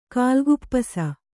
♪ kālguppasa